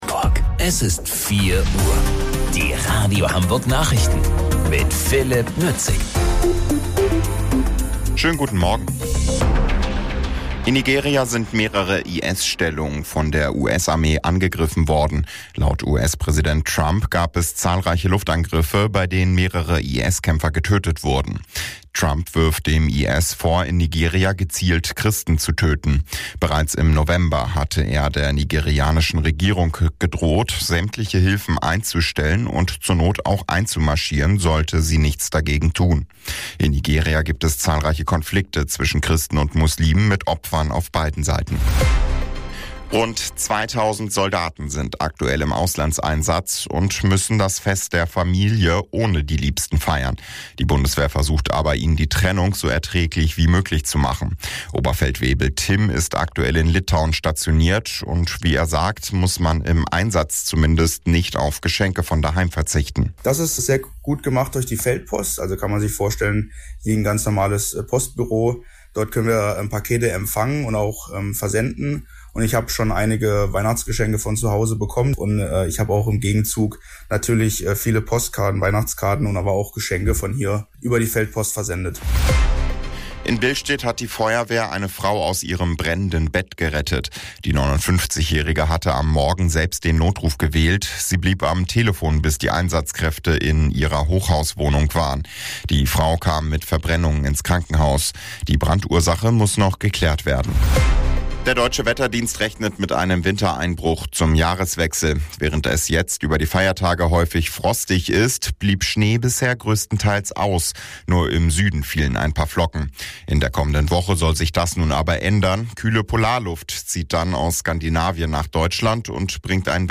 Radio Hamburg Nachrichten vom 26.12.2025 um 04 Uhr